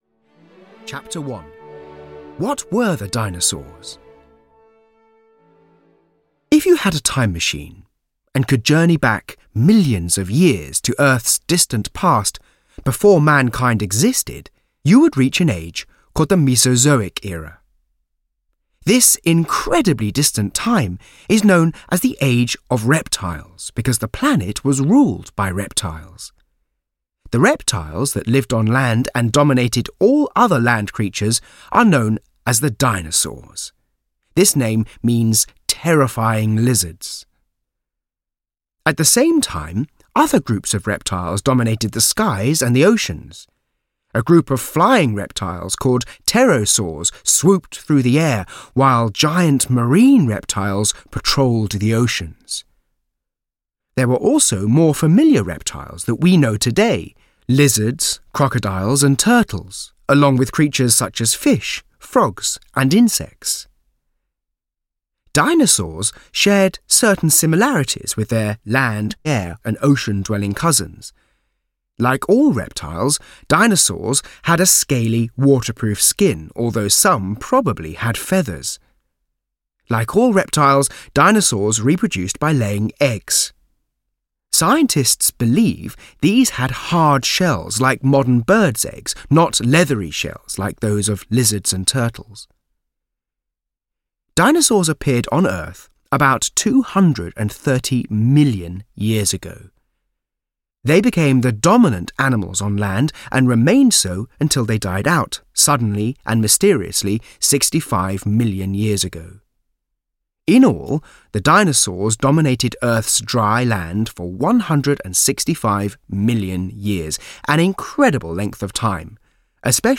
Dinosaurs (EN) audiokniha
Ukázka z knihy